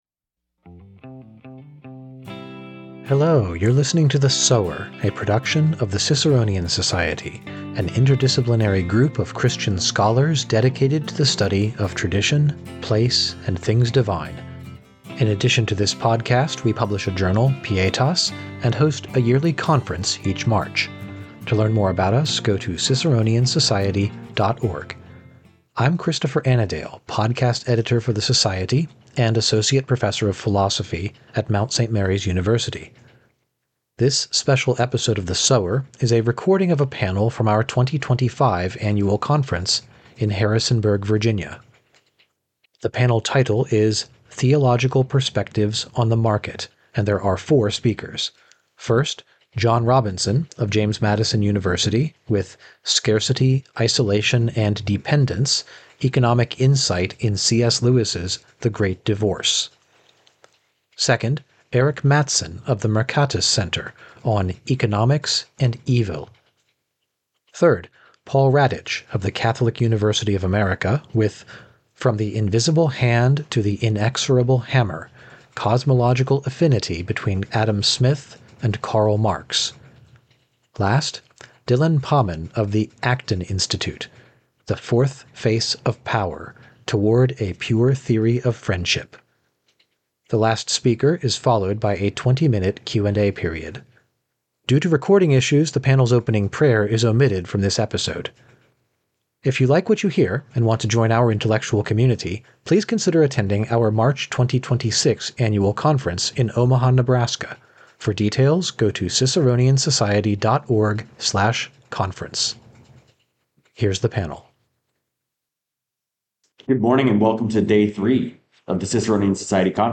This special episode is a recording from our 2025 Conference in Harrisonburg, Virginia.